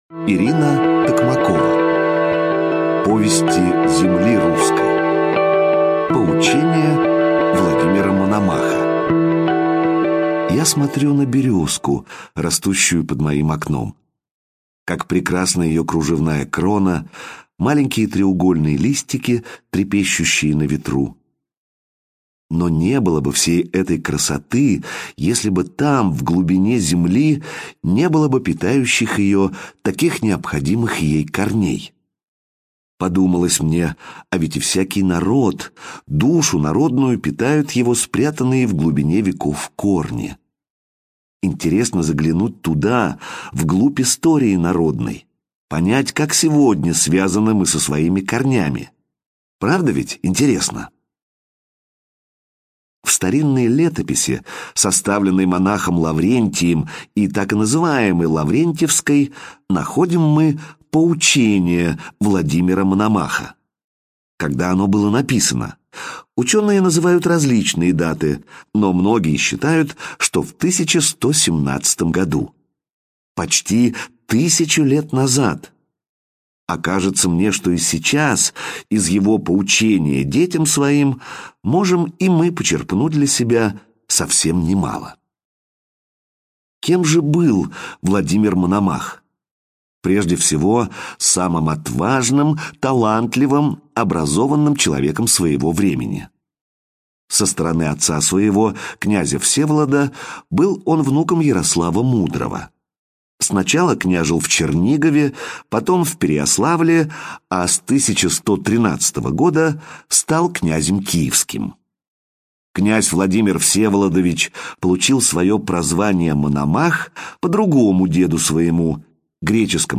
Повести земли Русской — слушать аудиосказку Ирина Токмакова бесплатно онлайн